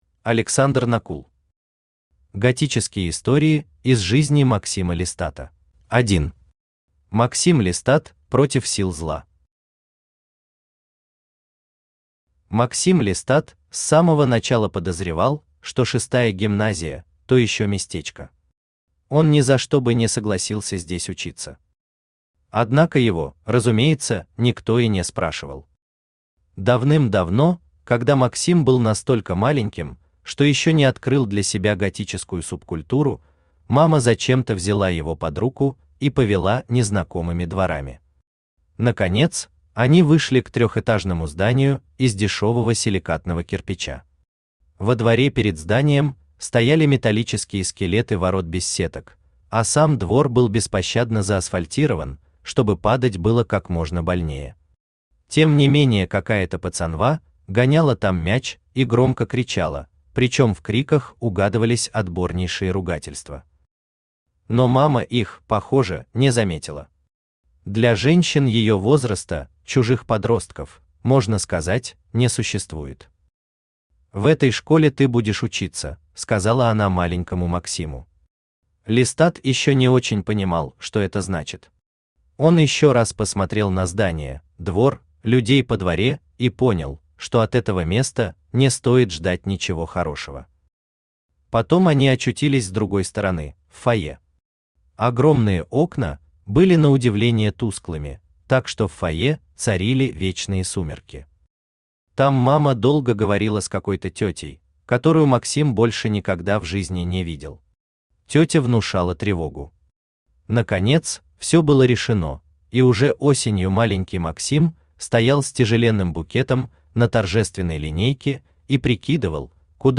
Аудиокнига Готические истории из жизни Максима Лестата | Библиотека аудиокниг
Aудиокнига Готические истории из жизни Максима Лестата Автор Александр Накул Читает аудиокнигу Авточтец ЛитРес.